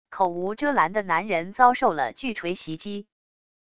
口无遮拦的男人遭受了巨锤袭击wav音频